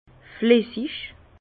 Prononciation 67 Herrlisheim